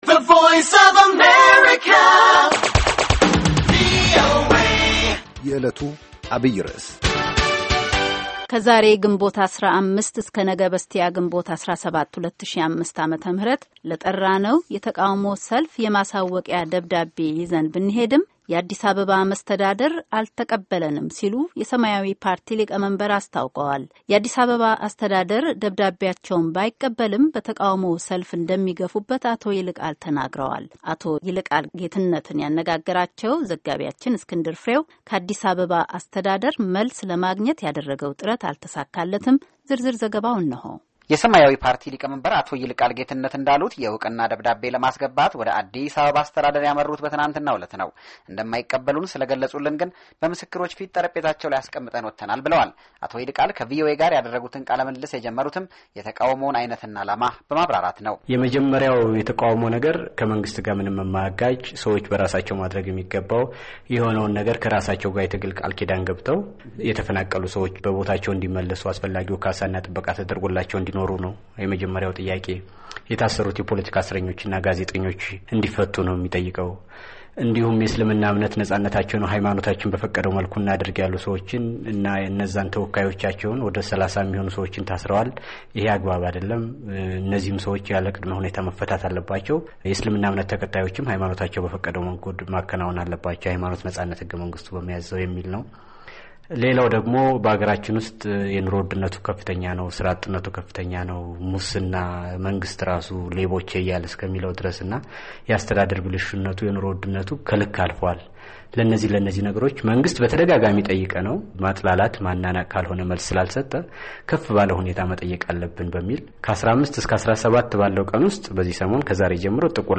የአዲስ አበባ አስተዳደር ደብዳቤአቸውን ባይቀበልም በተቃውሞው ሠልፍ እንደሚገፉበት አቶ ይልቃል ተናግረዋል፡፡ አቶ ይልቃል ጌትነትን ያነጋገረው ዘጋቢአችን...